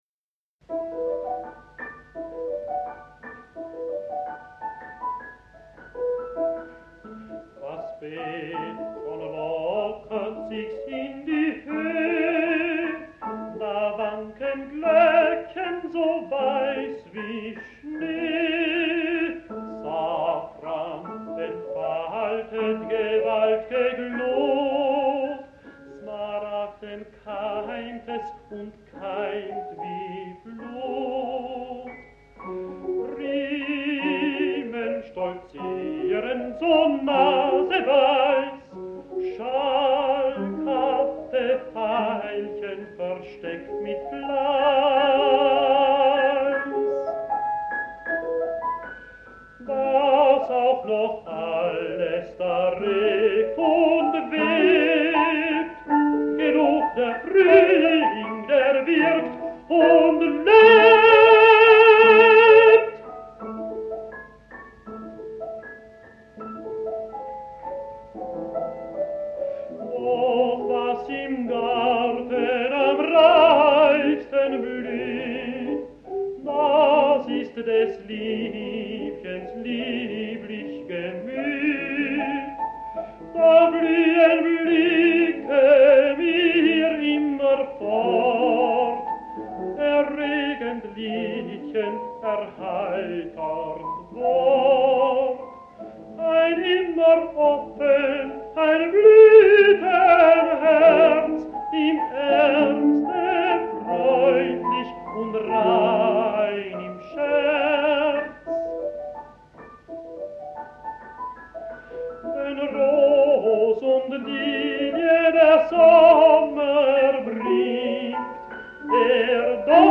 Aufnahmen aus den 1950er und frühen 1960er Jahren
Lied: